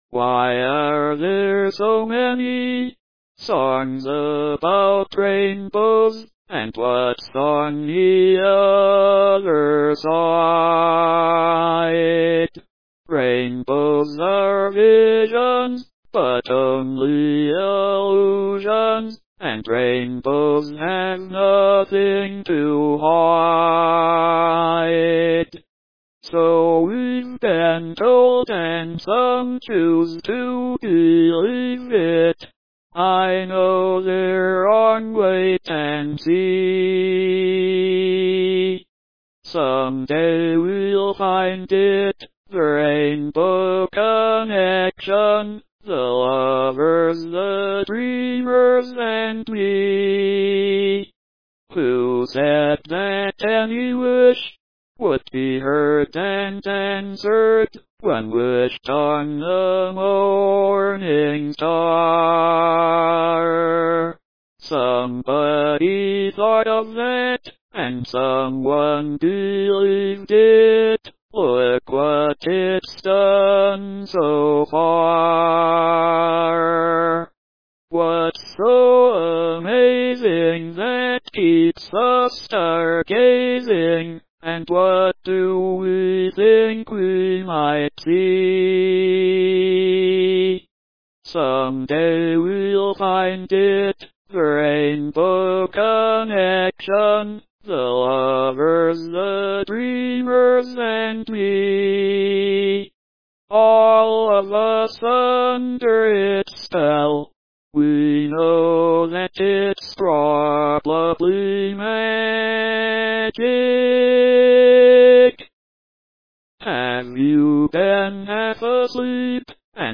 the old classic text-to-speech system